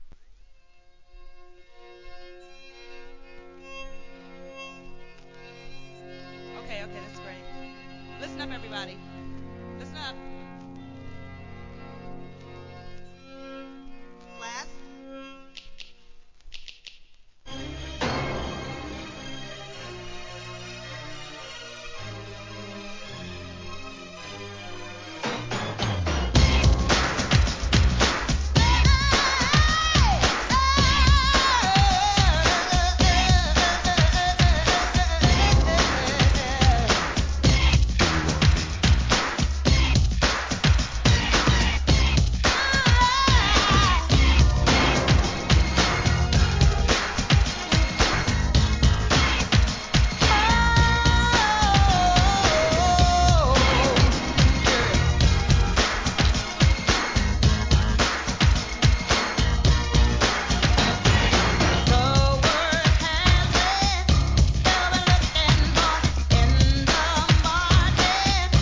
HIP HOP/R&B
前半はSLOW〜バラードを中心にじっくり聴かせ、後半はNEW JACK SWING!!